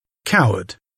coward [ˈkaʊəd]